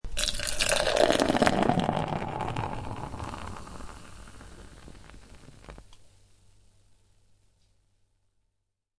ice_cold_drink.wav